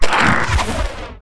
带人声的发射武器zth070518.wav
通用动作/01人物/02普通动作类/带人声的发射武器zth070518.wav
• 声道 單聲道 (1ch)